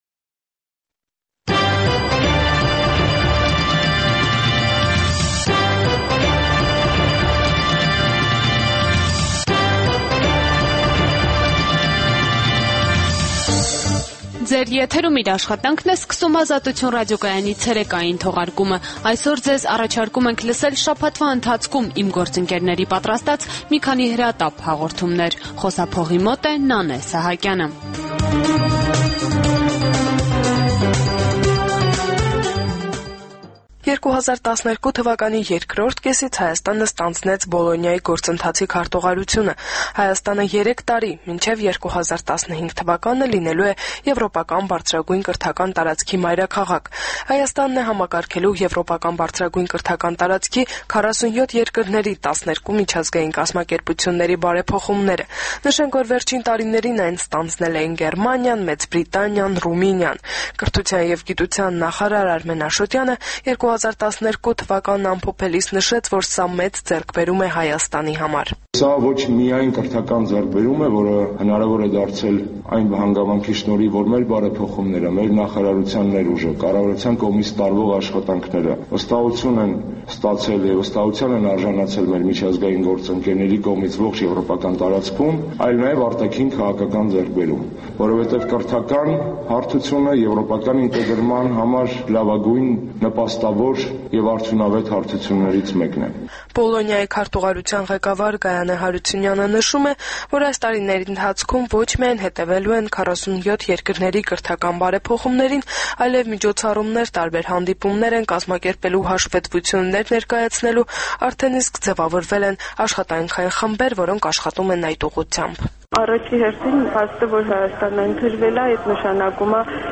Տեղական եւ միջազգային լուրեր, երիտասարդությանը առնչվող եւ երիտասարդությանը հուզող թեմաներով ռեպորտաժներ, հարցազրույցներ, երիտասարդական պատմություններ, գիտություն, կրթություն, մշակույթ: